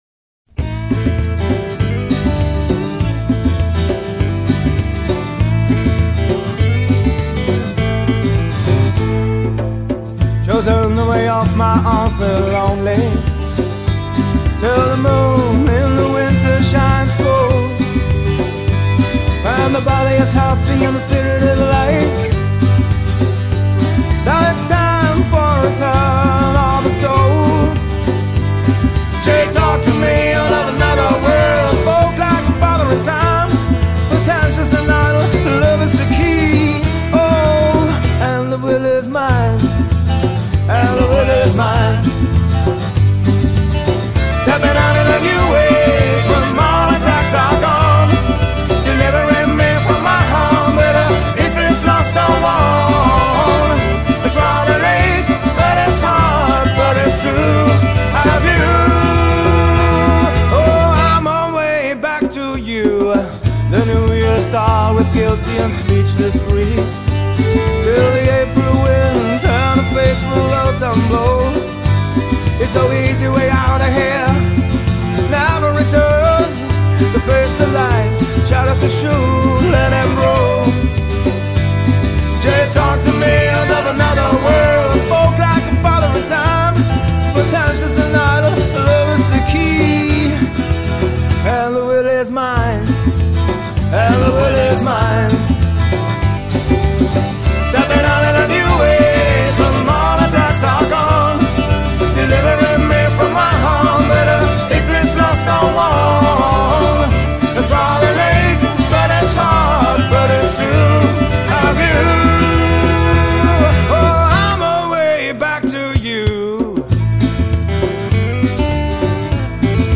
keyboards & violine